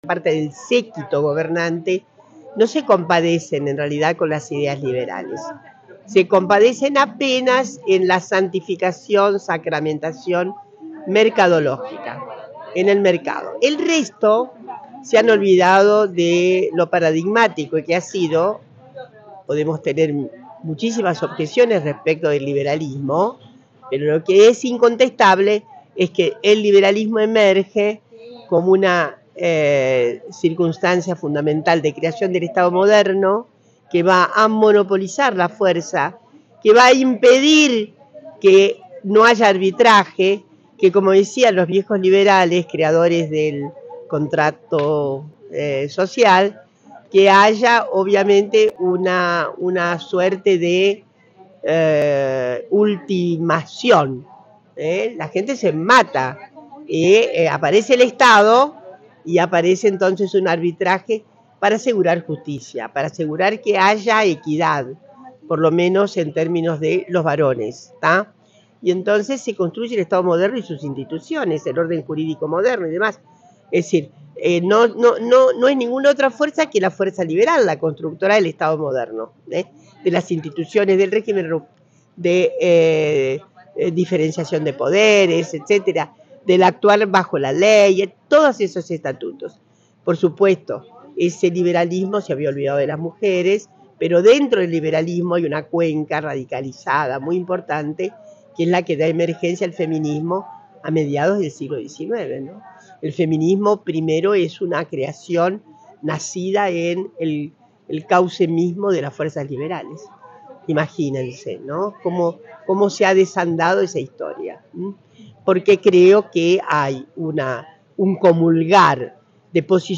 Escucha a continuación la entrevista que le hicieron los compañeres de “EL CIMBRONAZO” a Dora Barrancos en San Juan: